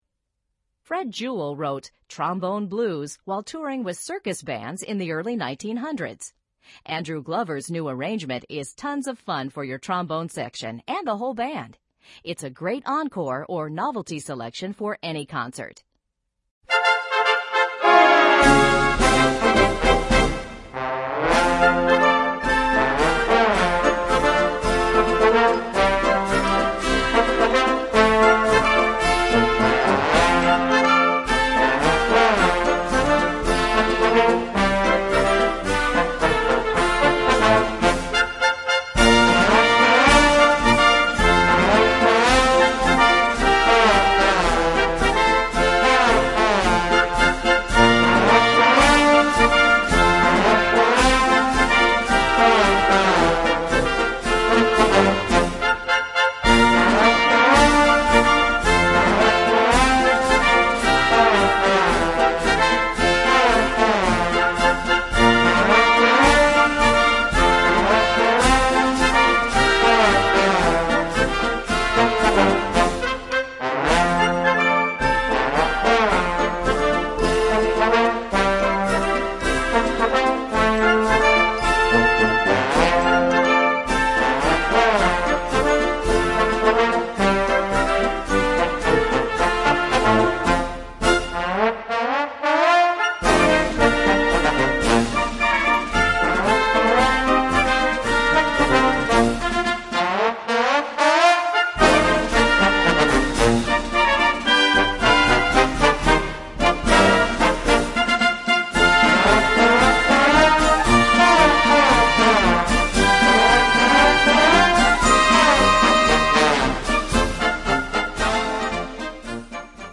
Voicing: Trombone Section w/ Band